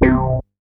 MoogVoco 007.WAV